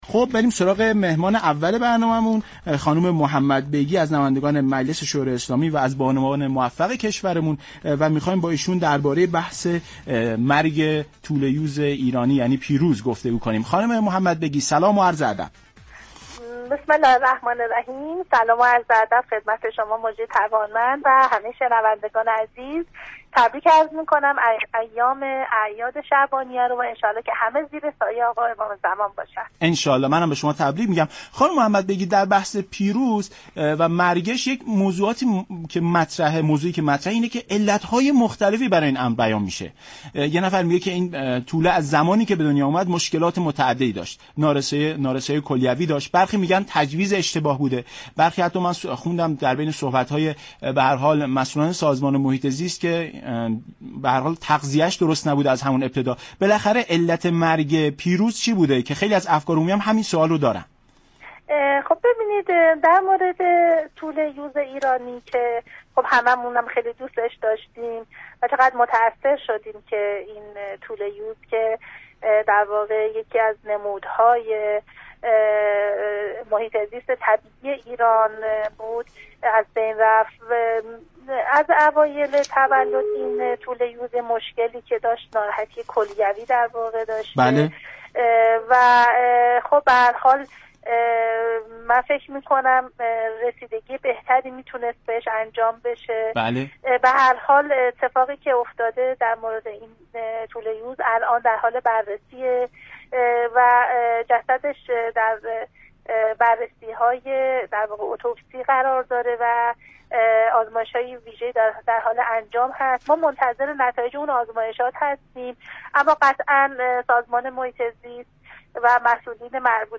دریافت فایل به گزارش شبكه رادیویی ایران، «فاطمه محمد بیگی» عضو كمیسیون بهداشت و درمان مجلس شورای اسلامی در برنامه «ایران امروز» درباره مرگ یوز ایرانی (پیروز) گفت: یوز ایرانی (پیروز) به عنوان یكی از نمادهای محیط زیست ایران از آغاز تولد با مشكلات كلیوی روبرو بوده است.